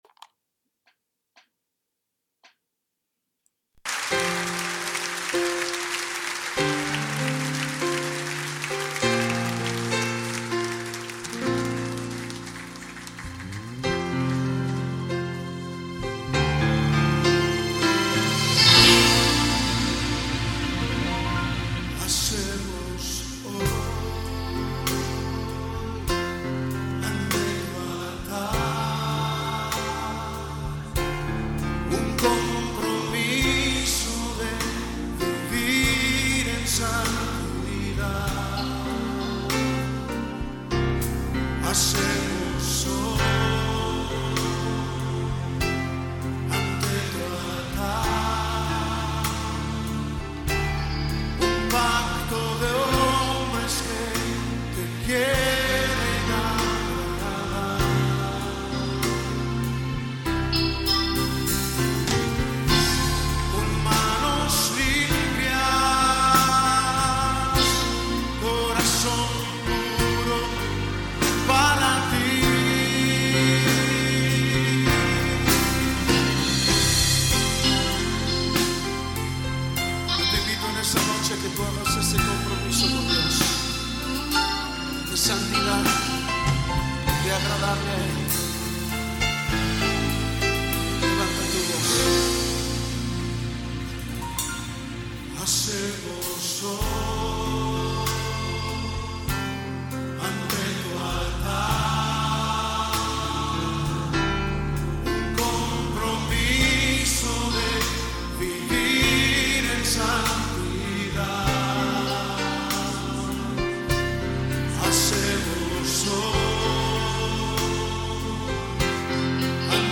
INTEGRIDAD PREDICA #2
INTEGRIDAD-PREDICA-2mp3.mp3